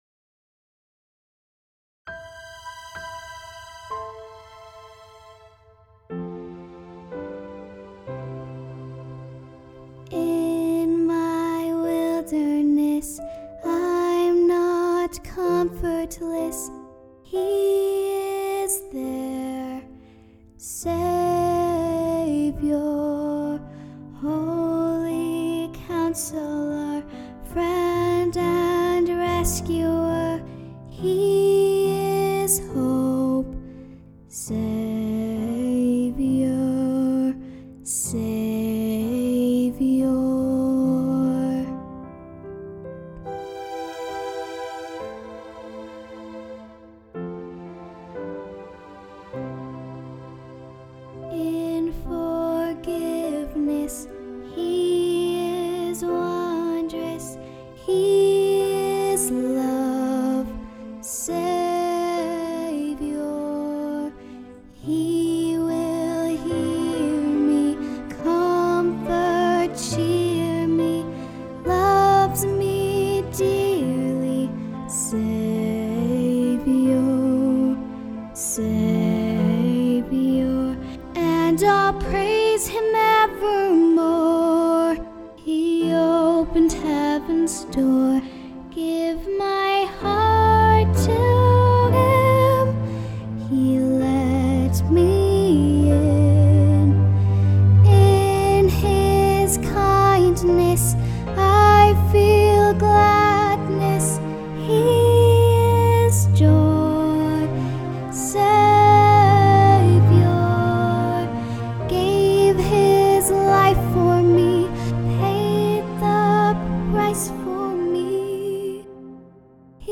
Vocal Solo